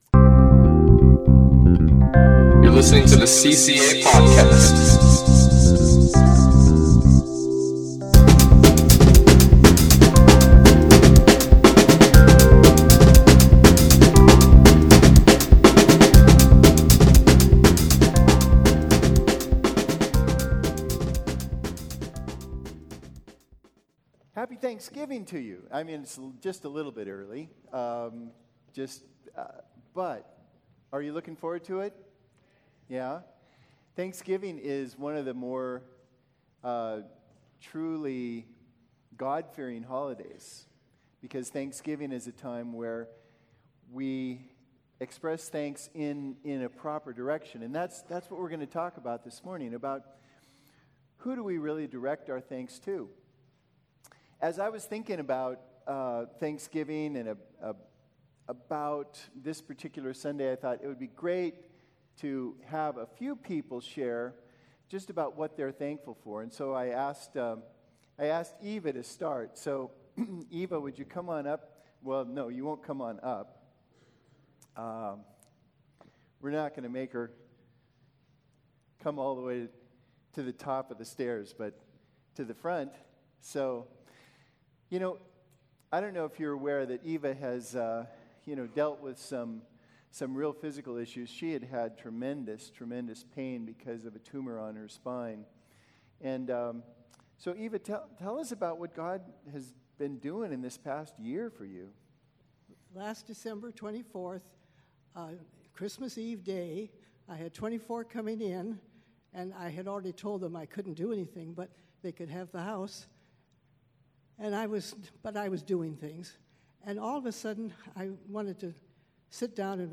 interviews three people with their stories of thankfulness